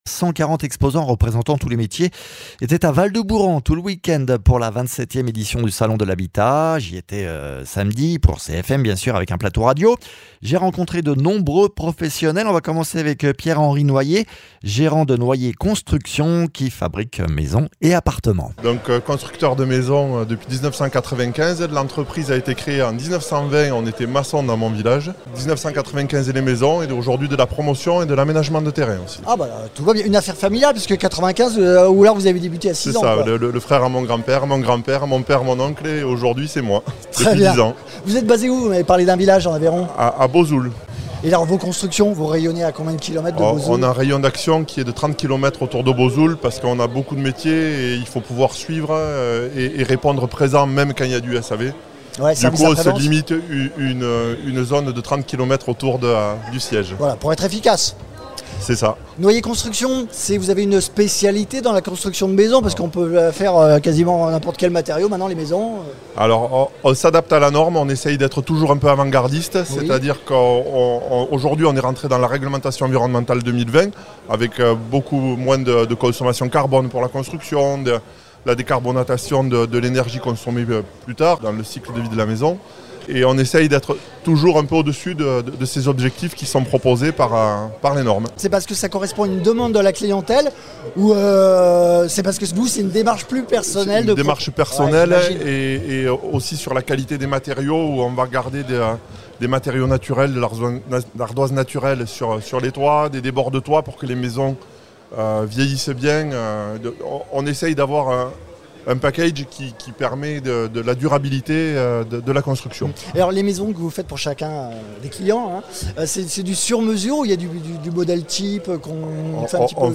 140 exposants participaient au salon de l’habitat du 11 au 14 novembre à Rodez, CFM était présent avec un studio mobile à la rencontre de ces professionnels
Interviews